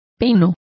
Complete with pronunciation of the translation of pines.